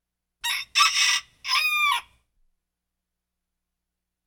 Bird-Rooster Crowing